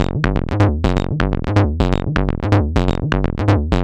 Synthetic 03.wav